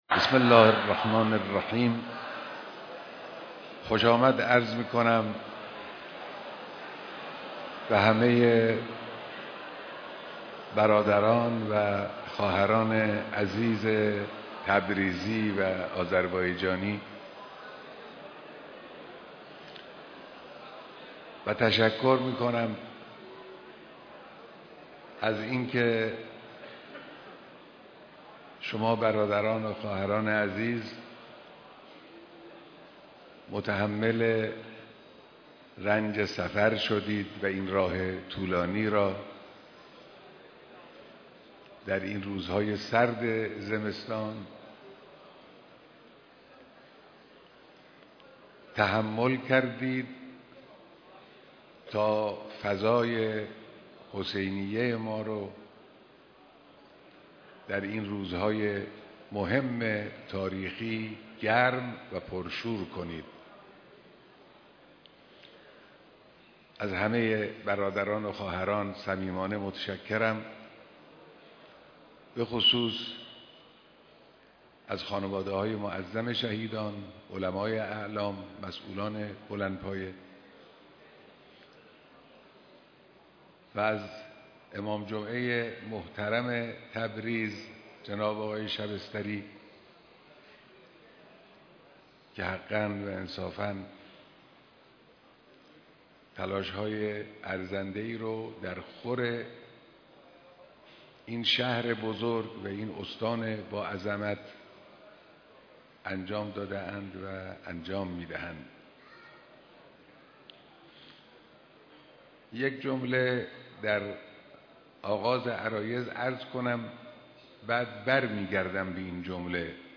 بیانات در دیدار پر شور هزاران نفر از مردم آذربایجان